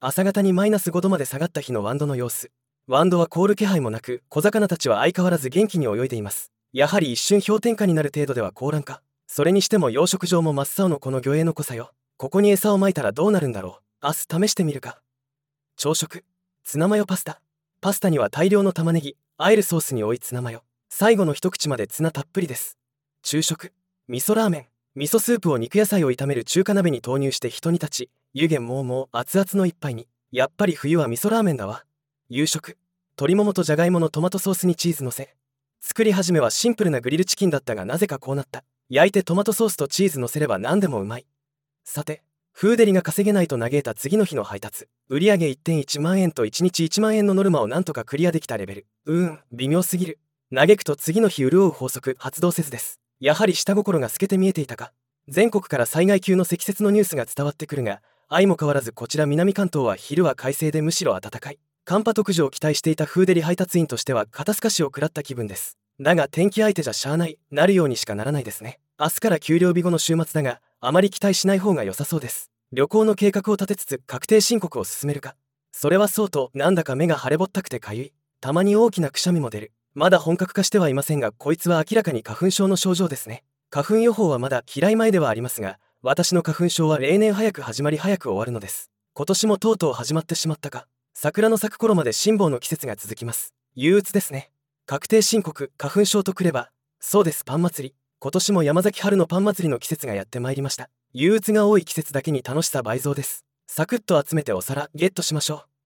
朝方に-5℃まで下がった日のワンドの様子。